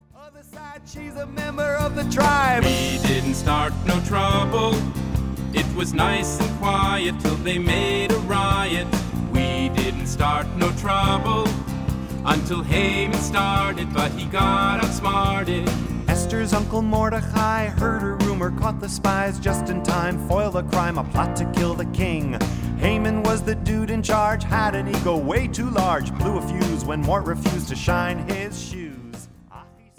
giving their music a uniquely modern sound.